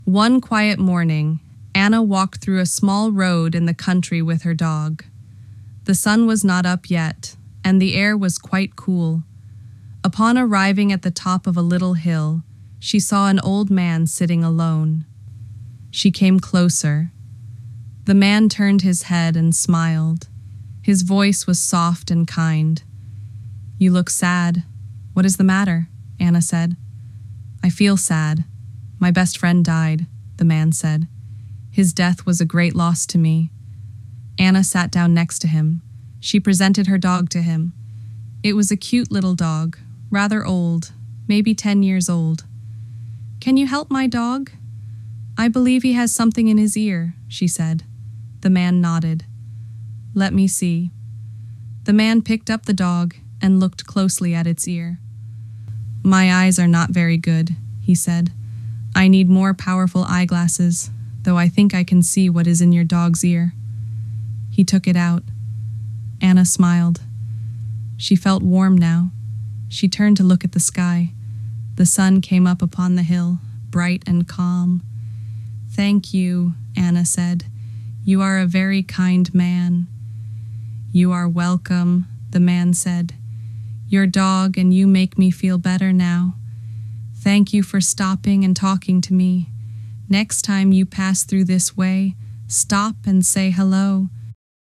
Anna-and-the-old-man-11Labs-Edited.mp3